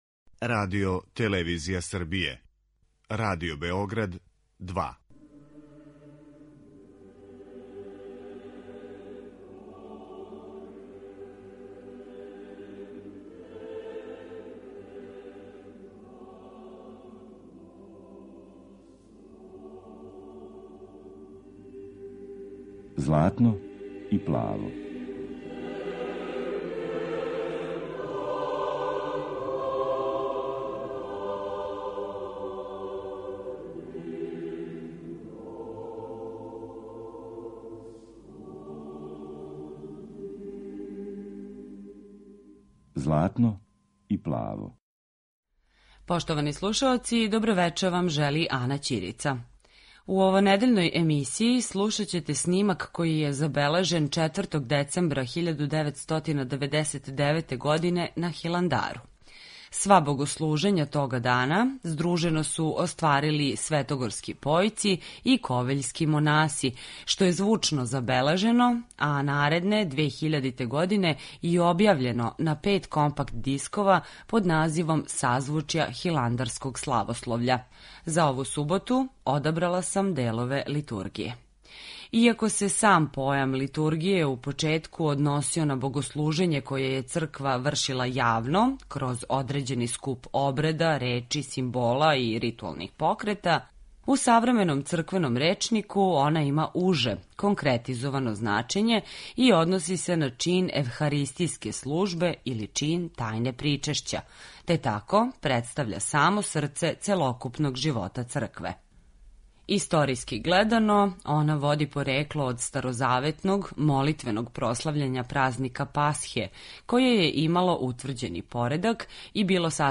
Емисија посвећена православној духовној музици.
Емитујемо снимак који је забележен на Ваведење 1999. године, а који су здружено остварили светогорски појци и и ковиљски монаси.